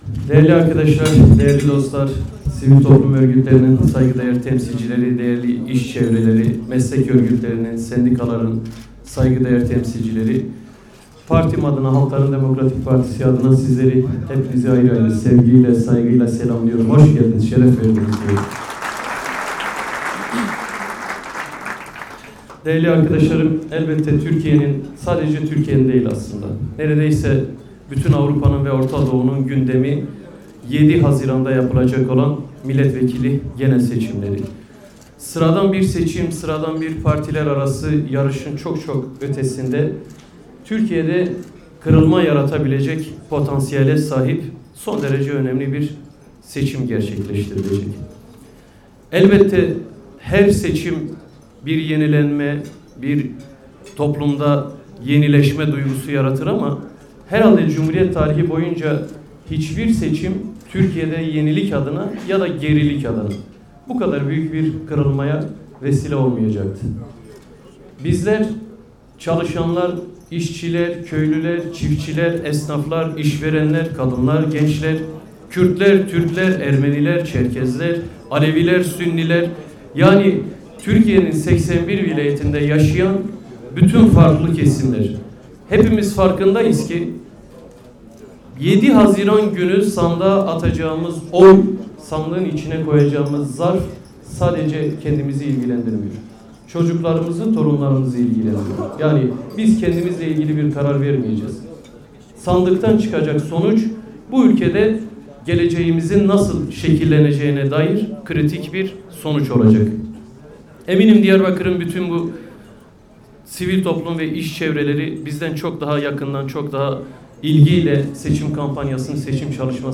Selahattin Demirtaş'ın Diyarbakırlı işadamlarına konuşması